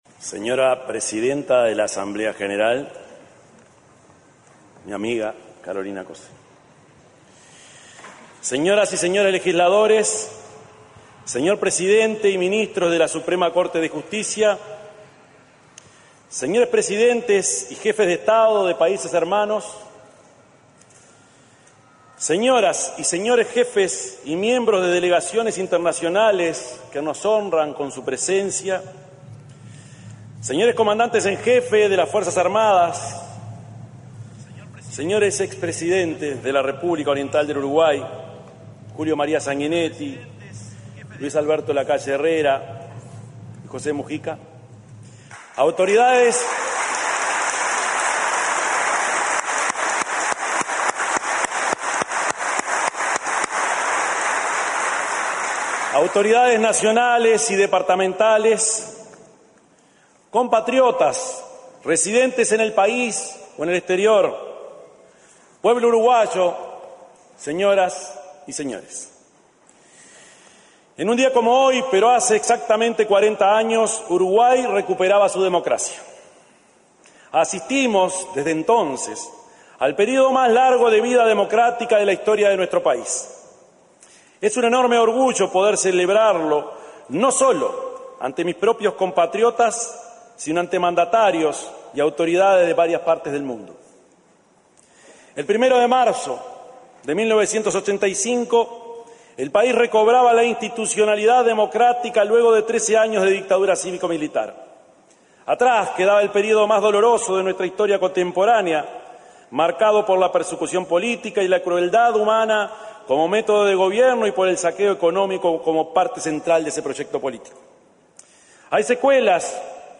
Palabras del presidente Yamandú Orsi
Este sábado 1.° de marzo, en oportunidad del traspaso de mando presidencial, se expresó el mandatario de la República, profesor Yamandú Orsi.